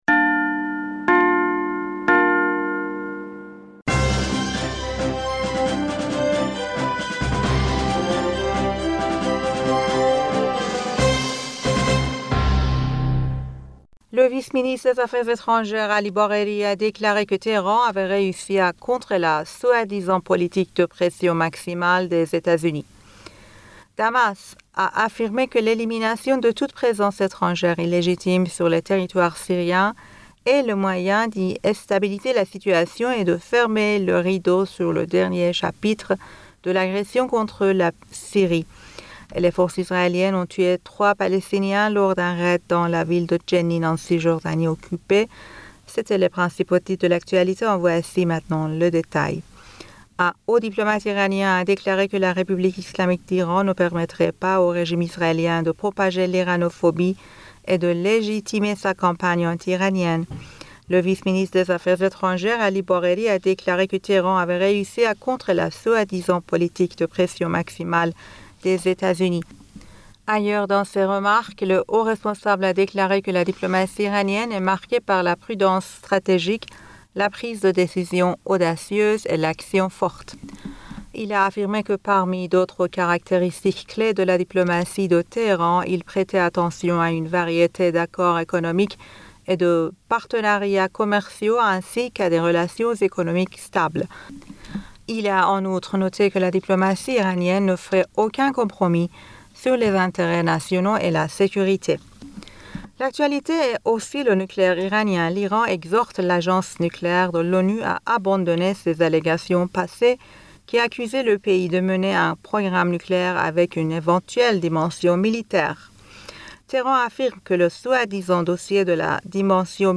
Bulletin d'information Du 17 Juin